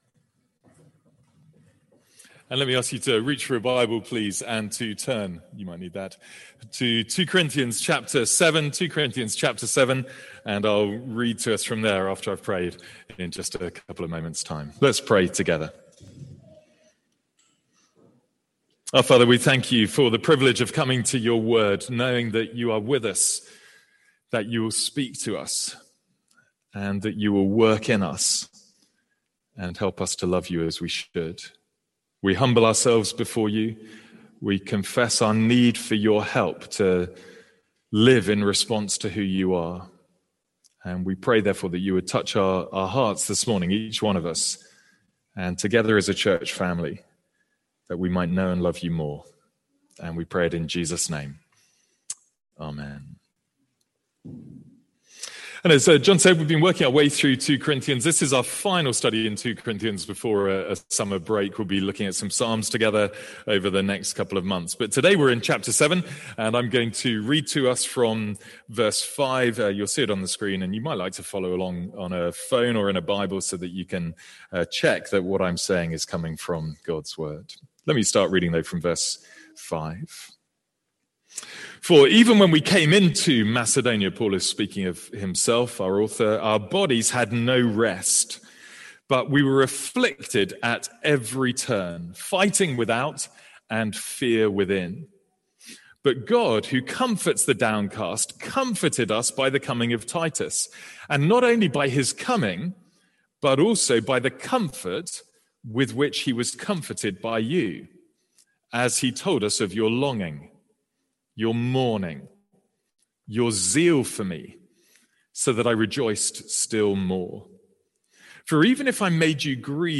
Sermons | St Andrews Free Church
From the morning series in 2 Corinthians.